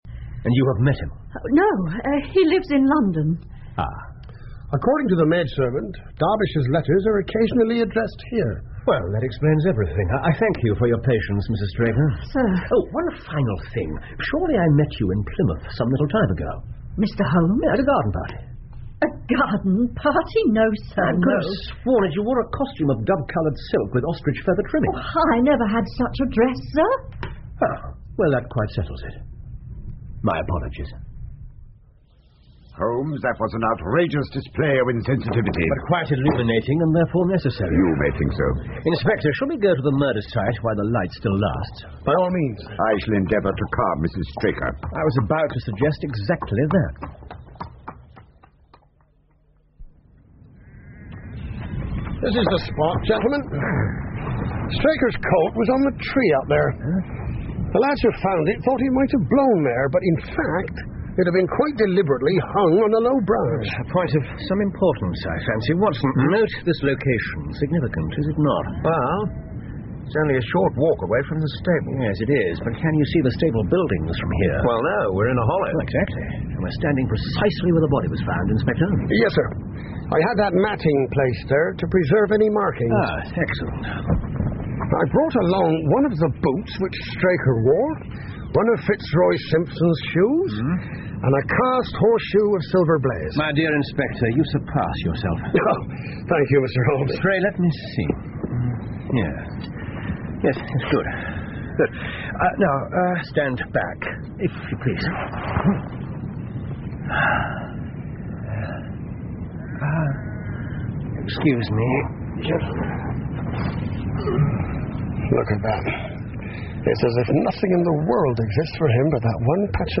福尔摩斯广播剧 Silver Blaze 5 听力文件下载—在线英语听力室